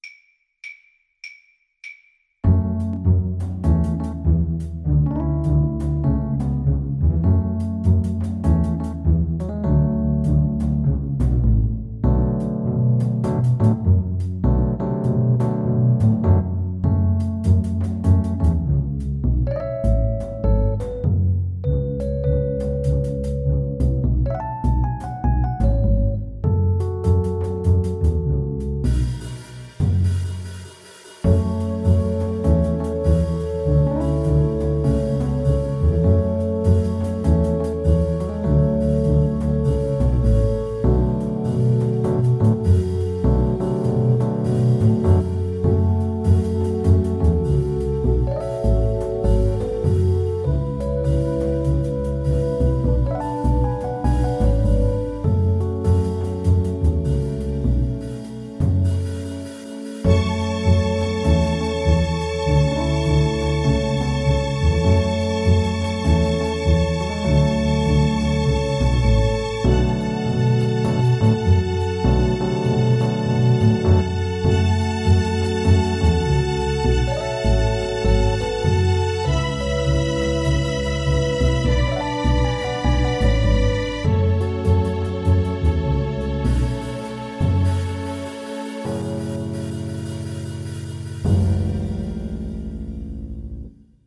A simple blues tune with an improvisatory section
With a swing!
4/4 (View more 4/4 Music)